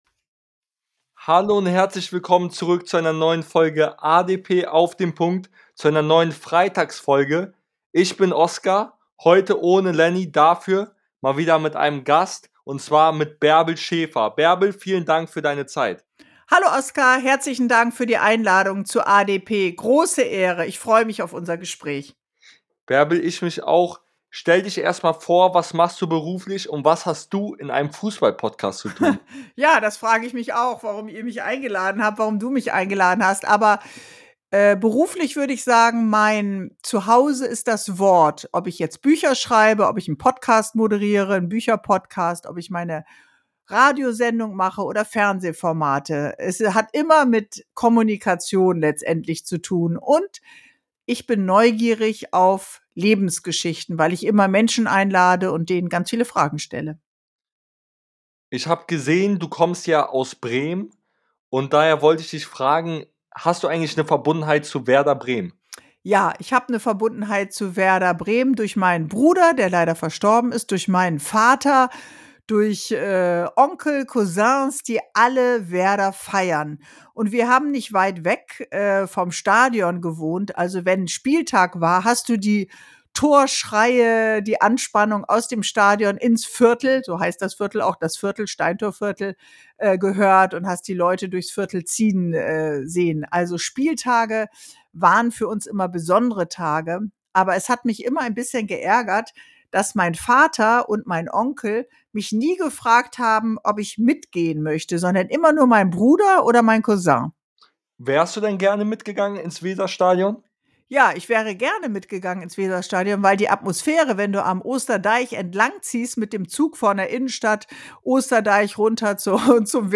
TV und Radio Moderatorin Bärbel Schäfer